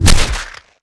clawshit2.wav